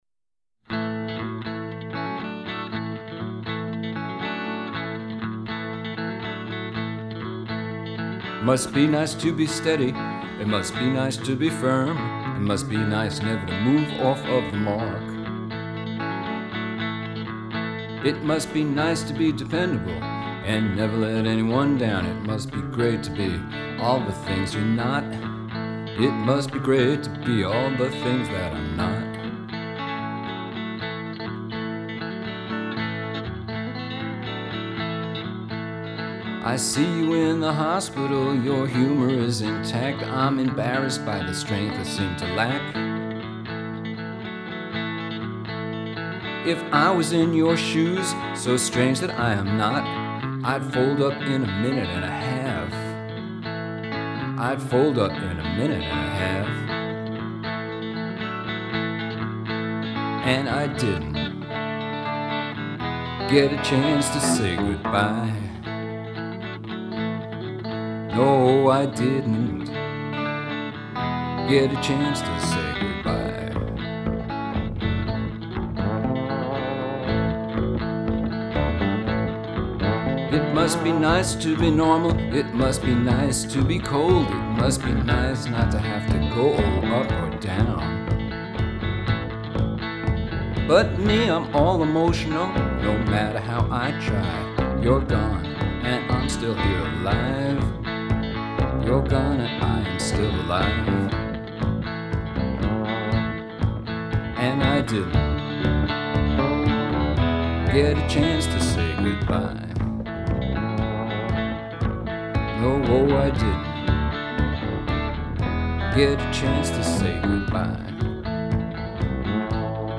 with a “walking” style of guitar playing